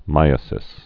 (mīə-sĭs, mī-īə-sĭs)